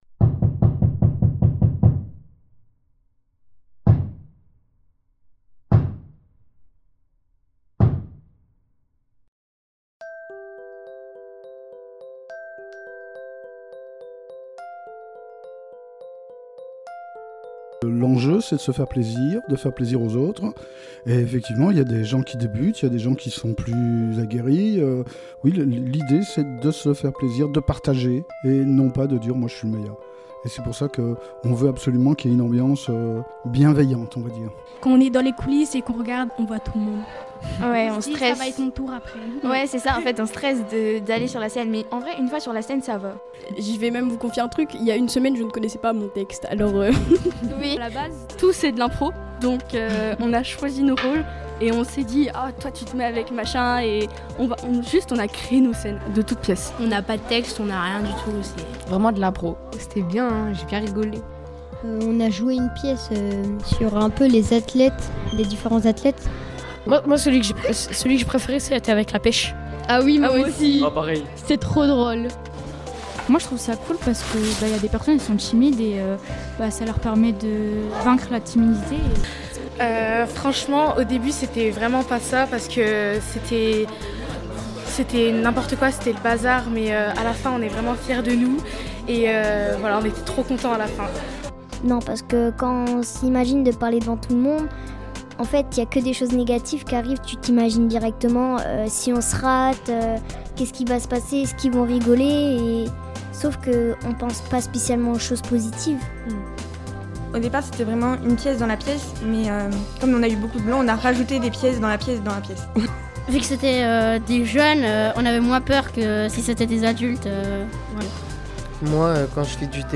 Le reportage de Radio Léo